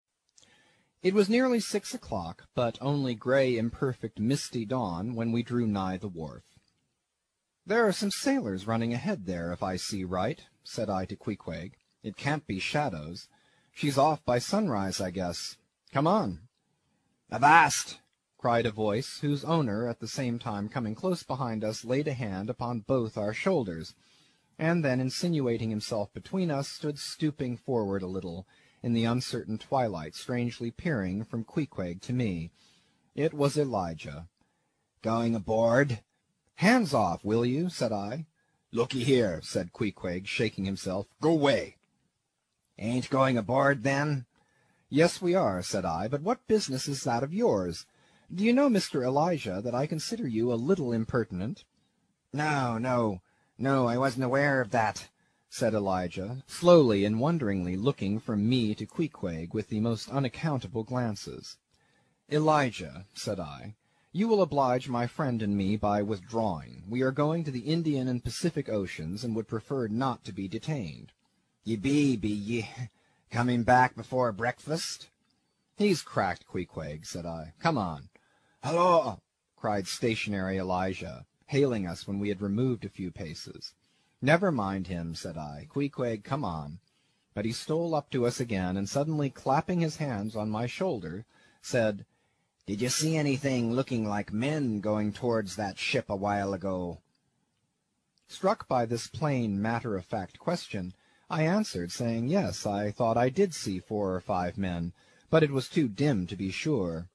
英语听书《白鲸记》第321期 听力文件下载—在线英语听力室